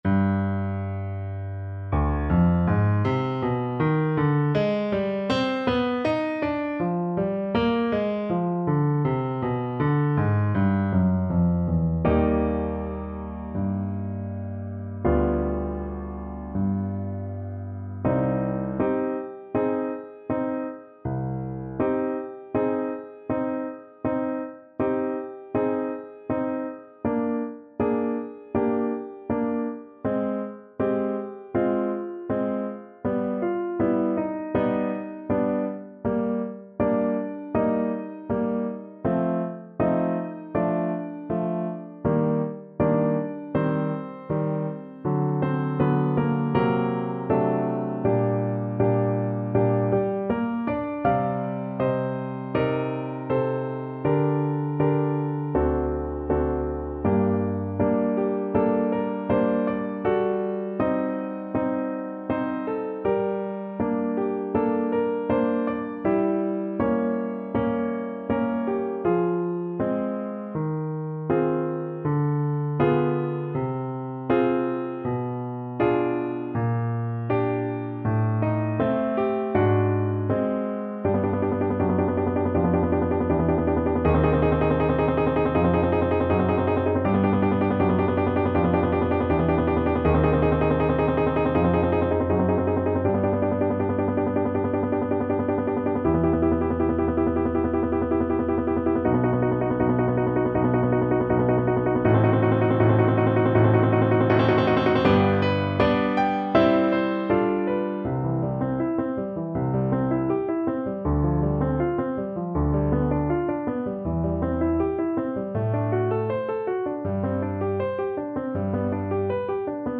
C minor (Sounding Pitch) D minor (Clarinet in Bb) (View more C minor Music for Clarinet )
~ = 100 Molto moderato =80
Classical (View more Classical Clarinet Music)